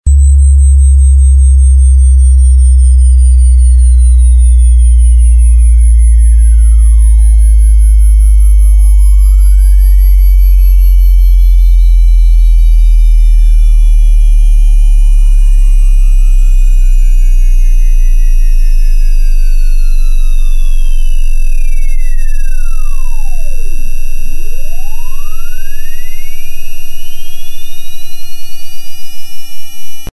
Эти аудиозаписи содержат высокочастотные сигналы и другие звуковые эффекты, неприятные для насекомых.
Ультразвуковой отпугиватель собак